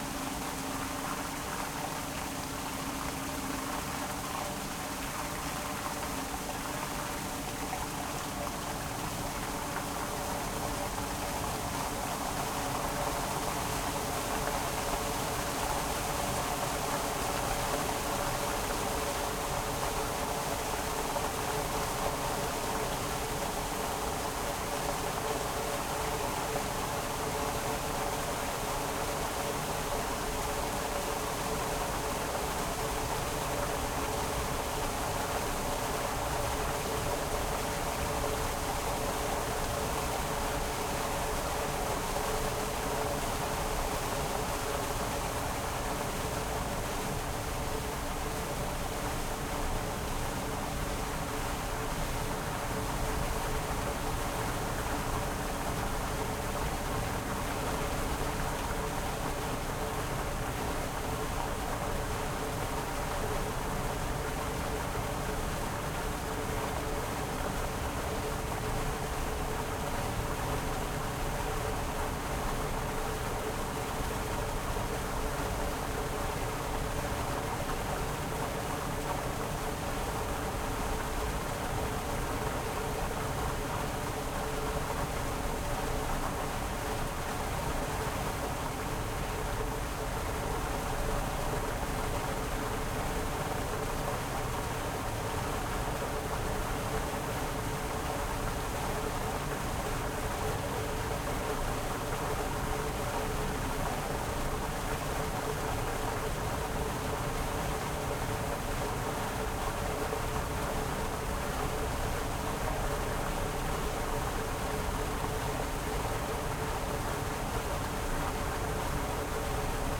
SW015_Piping_Systems_Waterworks_Tunnel_Surface_Water_Flow_Contact_Mic.ogg